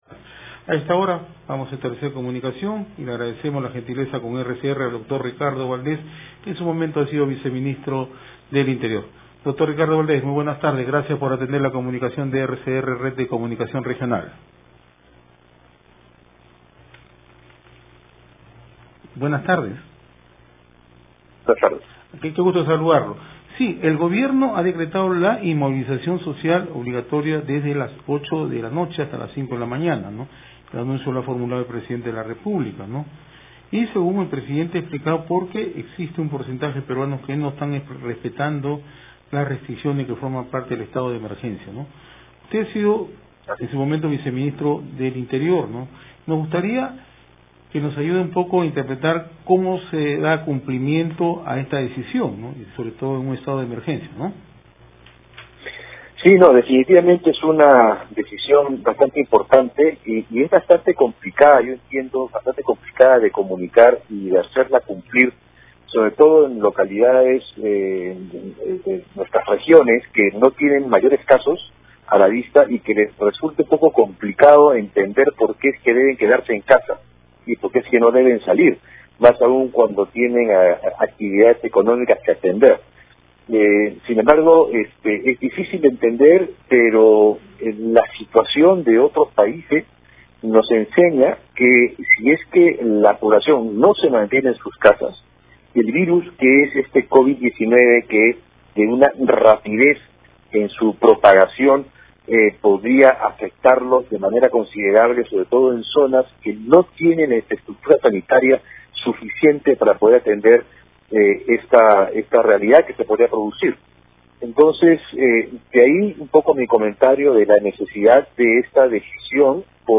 RCR, 19 de marzo de 2020.- Ricardo Valdés, viceministro del Interior, dijo que el Gobierno debería explicar cuáles serían las sanciones penales que se aplicarían a las personas que no se quedan en sus casas para evitar el contagio del Covid 19 y ponen en riesgo la vida de otras personas.